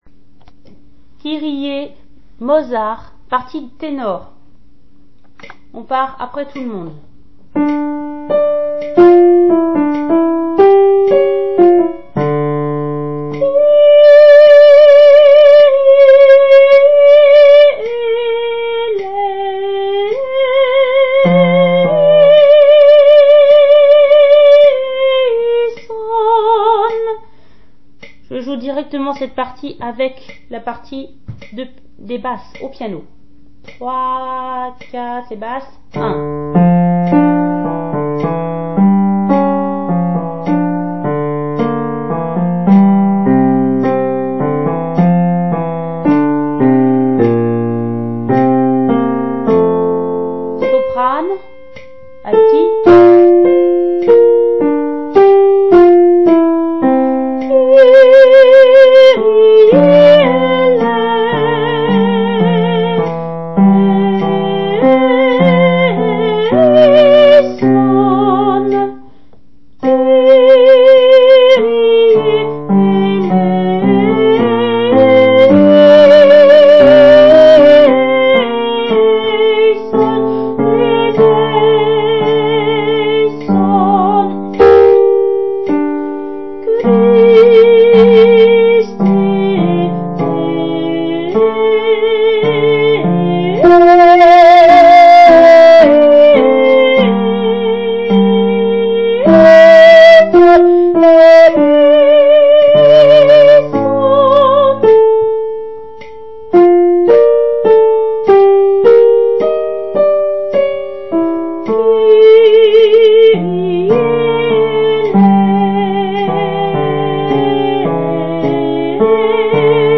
Choeur lyrique
enregistr�es voix par voix
Mozart_kyrie_KV90_tenor.MP3